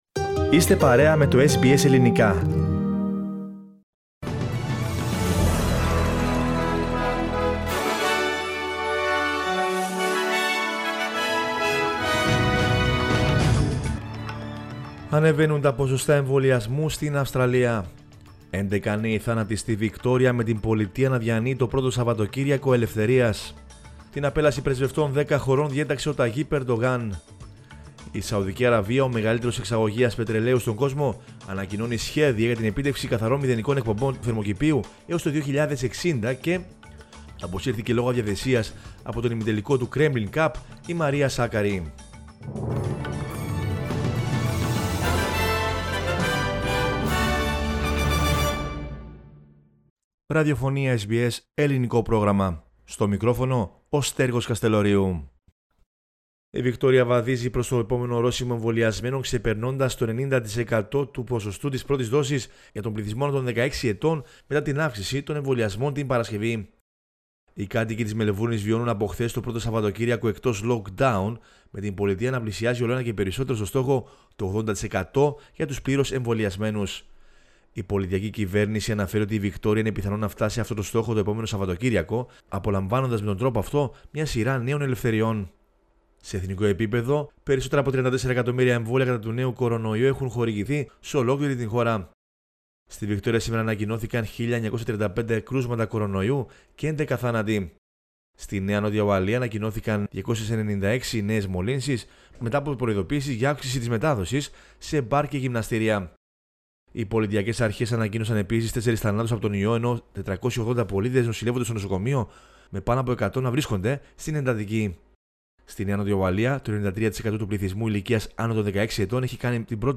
News in Greek from Australia, Greece, Cyprus and the world is the news bulletin of Sunday 24 October 2021.